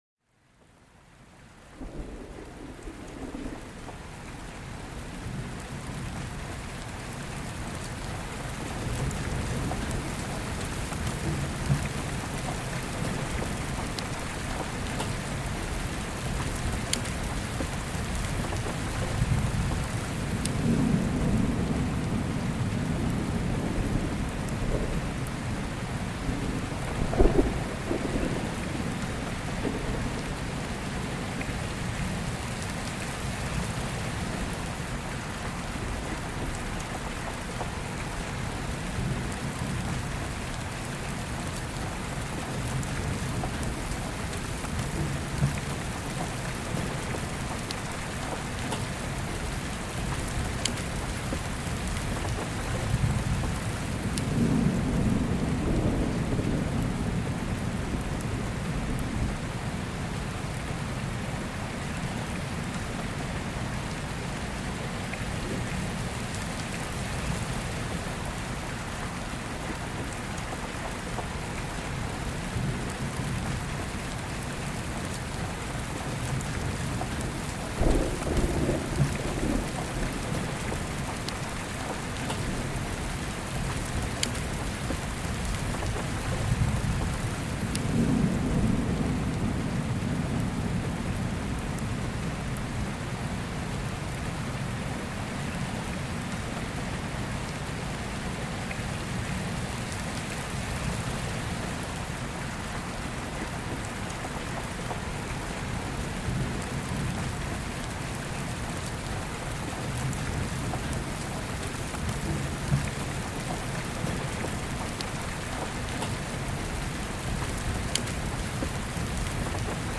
Tropical Rainfall to Wash Away Stress and Anxiety